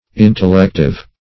Intellective \In`tel*lec"tive\, a. [Cf. F. intellectif.]